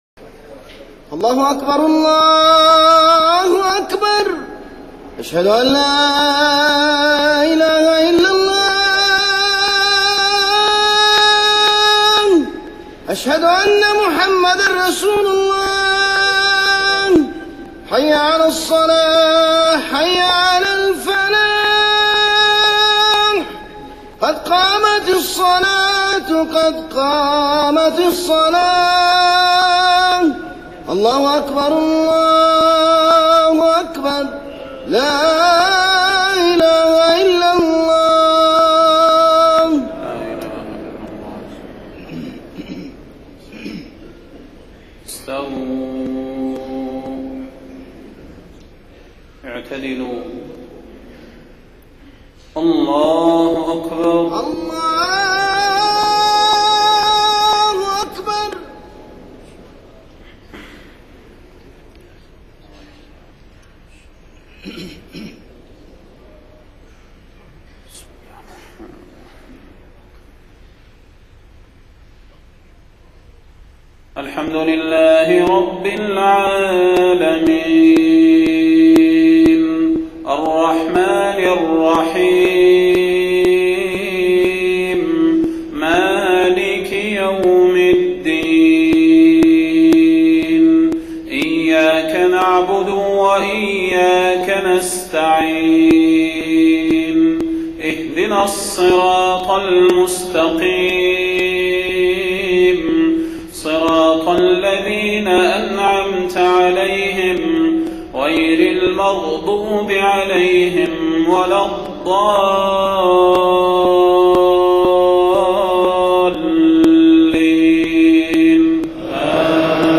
صلاة المغرب 5 صفر 1430هـ من سورة الأسراء 23-27 > 1430 🕌 > الفروض - تلاوات الحرمين